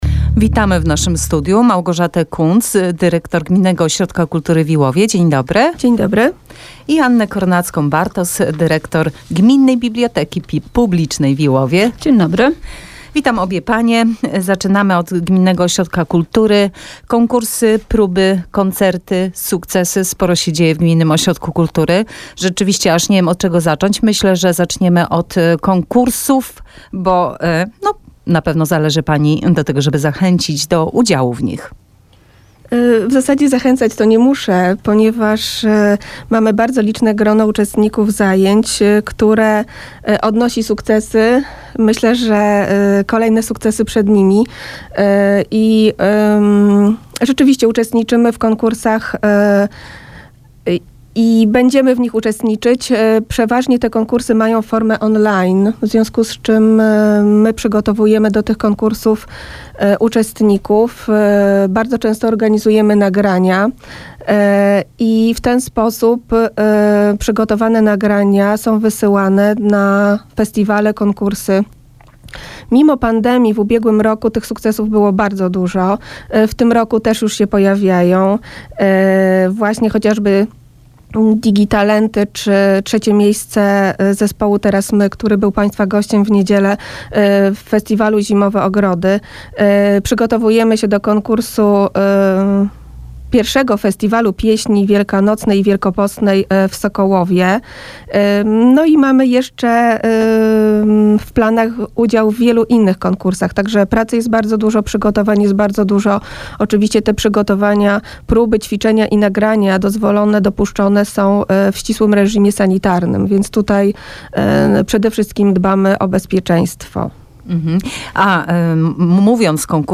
Wywiad